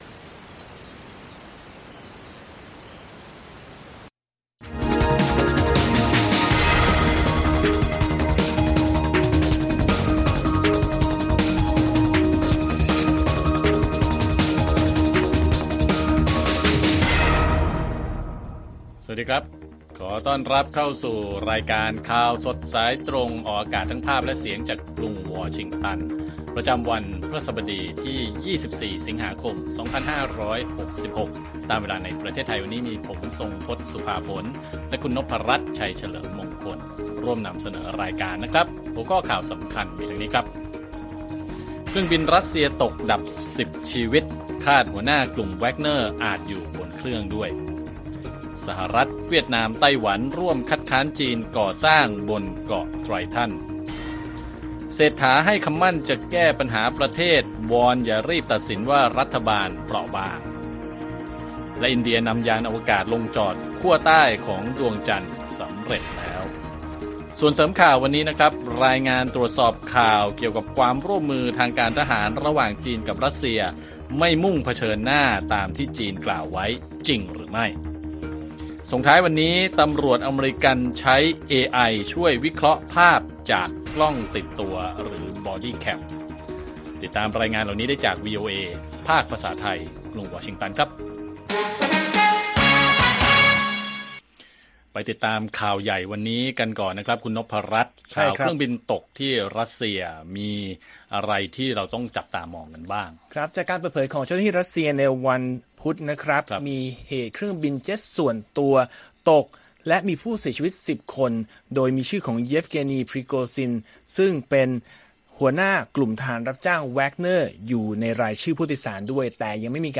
ข่าวสดสายตรงจากวีโอเอไทย พฤหัสบดี ที่ 24 ส.ค. 66